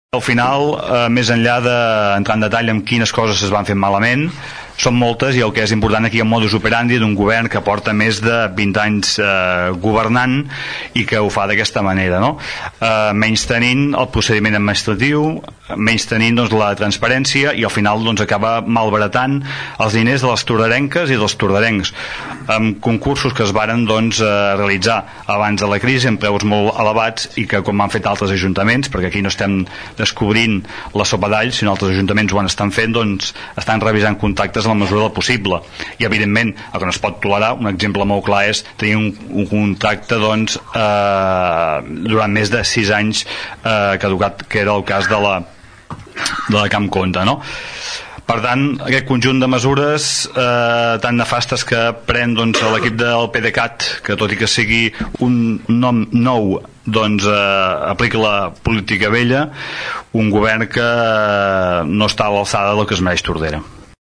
Per ERC+Gent de Tordera, el regidor Xavier Pla apuntava que un dels eixos de la seva campanya electoral va ser la transparència a l’hora de contractar.